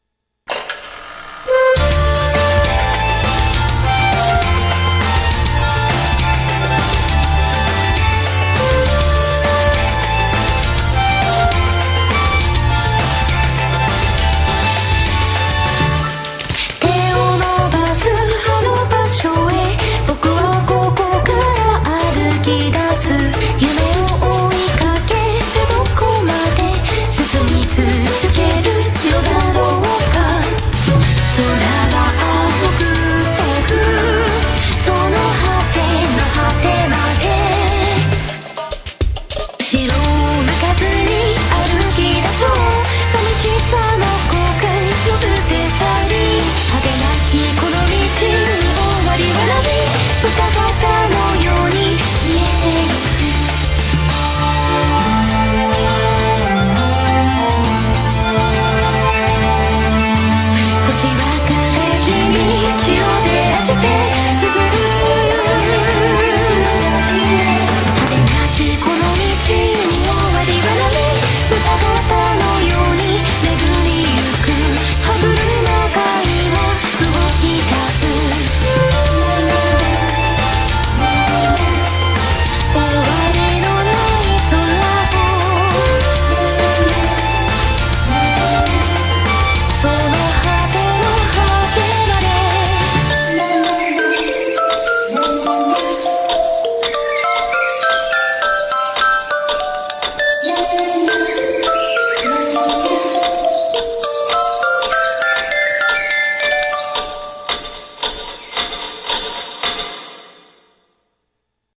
BPM203
Audio QualityPerfect (Low Quality)